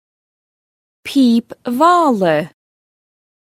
Translation: pipe(s) Bagpipe (unknown type) Synonym of Bag-pipe According the internet site a Scottish name; NB: ➺ Pìob mhala (Ireland). Sources Web LearnGaelic Dictionary [Find piob-mhala ], Amazon AWS (pronunciation).